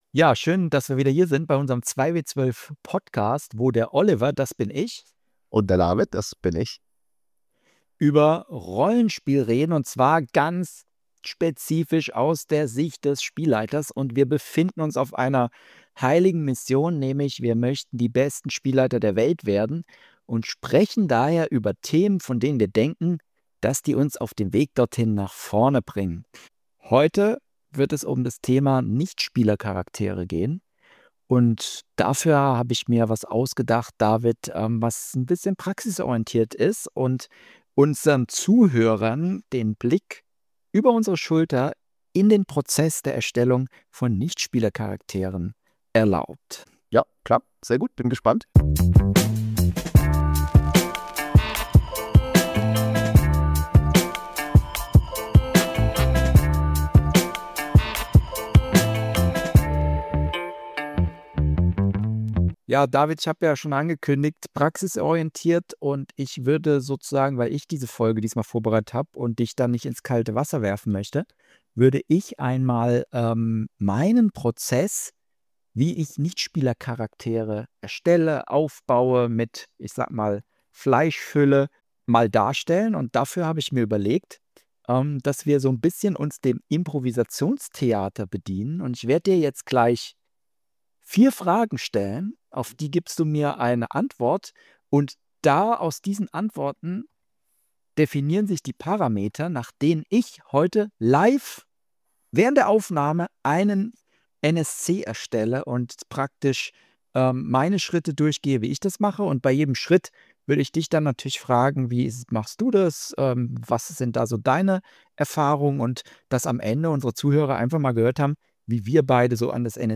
Mit einem kleinen Impro-Theater-Experiment liefern die beiden die Zutaten: Fantasy-Setting, ein potenzieller Verbündeter, ein „einfacher Menschenjäger“ – und als absurder Bonus ein einziges Wort: Brackwasser.